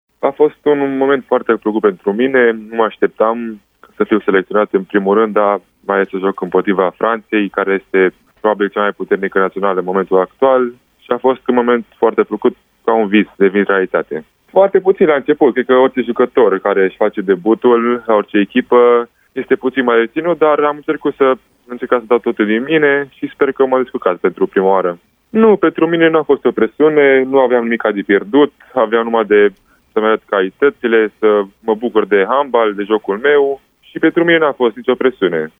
Invitat într-una dintre edițiile de week-end ale emisiunii Arena Radio